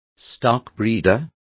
Complete with pronunciation of the translation of stockbreeders.